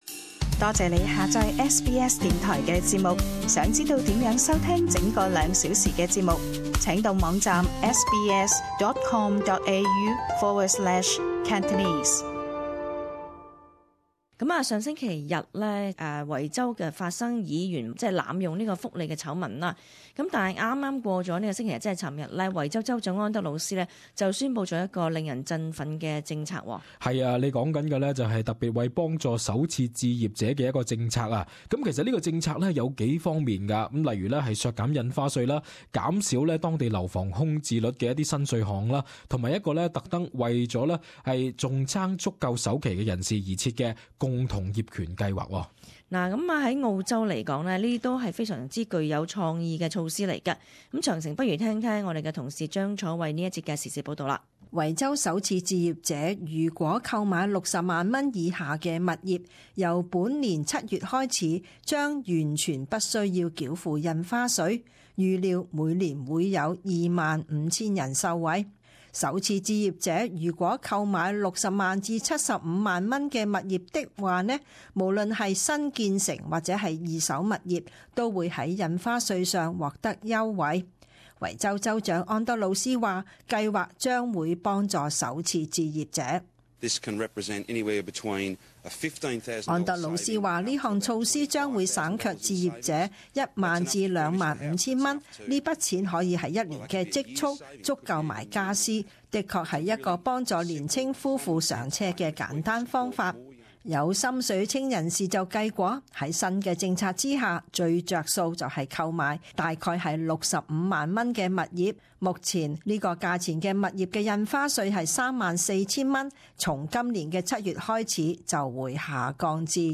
時事報導：維州政府豁免首置人士印花稅